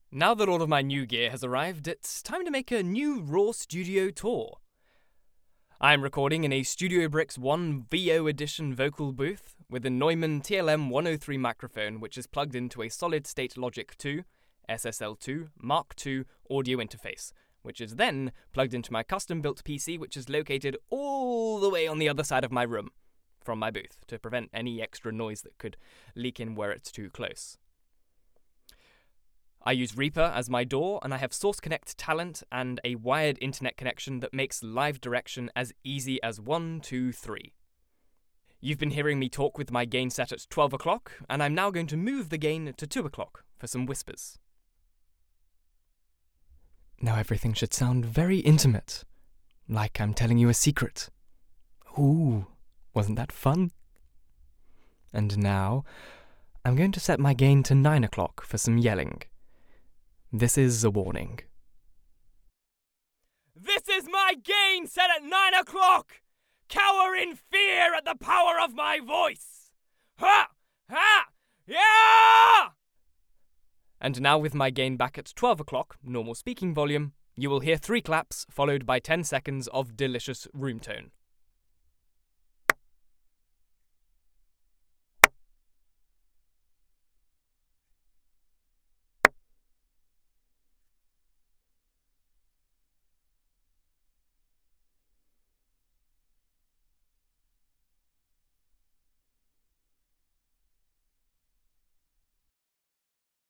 Male
English (British)
Yng Adult (18-29)
I speak in a tenor vocal range with a British RP accent. I have a sophisticated, warm, calming tone of voice.
Raw Home Studio Demo